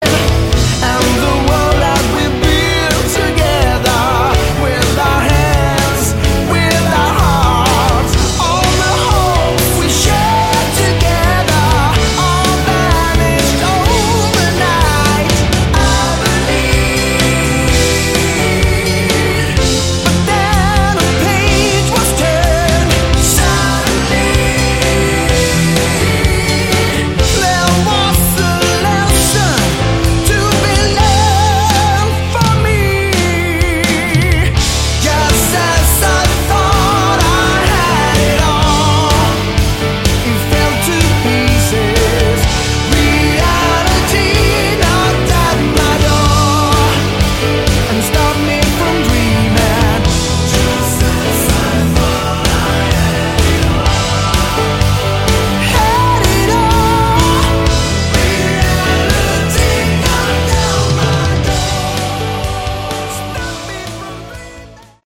Category: AOR
Drums
Keyboards, Backing Vocals
Guitars, Backing Vocals
Lead Vocals
Bass, Backing Vocals
Very Melodic.